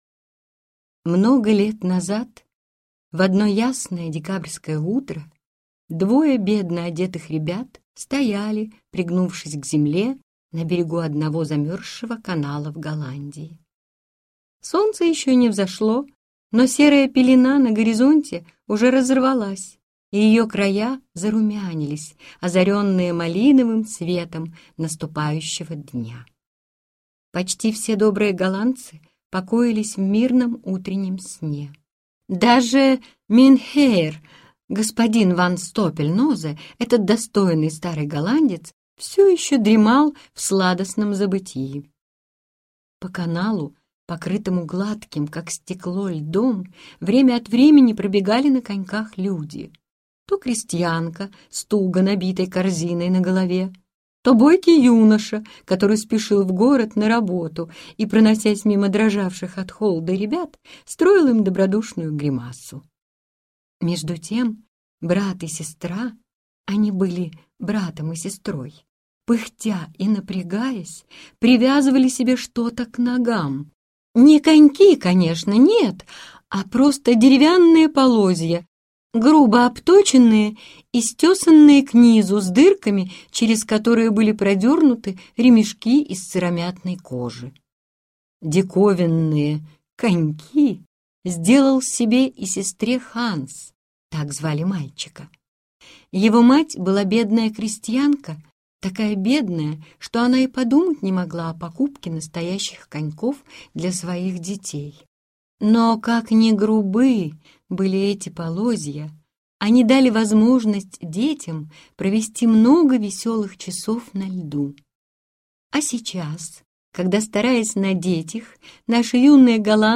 Аудиокнига Серебряные коньки | Библиотека аудиокниг